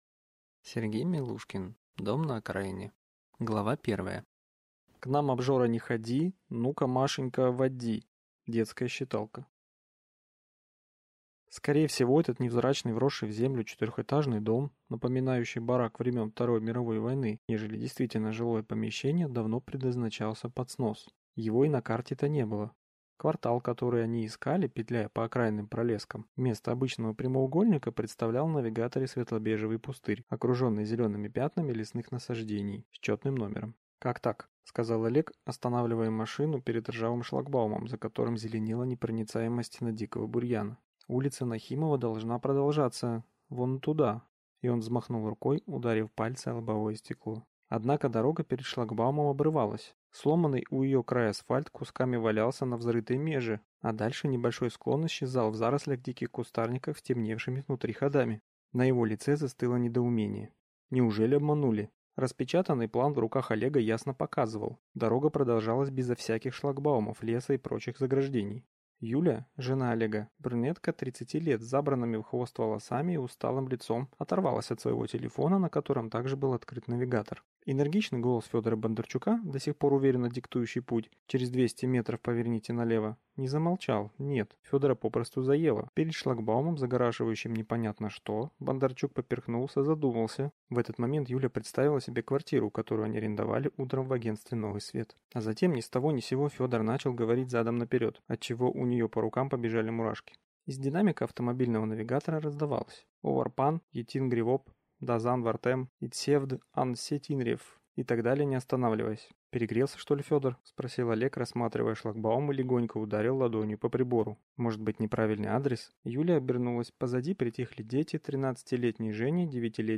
Аудиокнига Дом на окраине | Библиотека аудиокниг